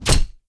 wrench_hit_wood1.wav